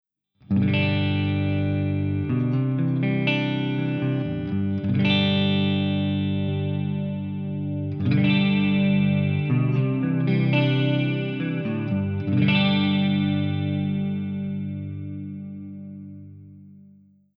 Based on a Marshall Amp Stack.
All tones were recorded via USB straight into Logic X.
No post FX were added.
1. Crystal Clear – CTL  Activates Stereo Chorus
SOUND SAMPLES: (CTL Effects are engaged during the sound demo)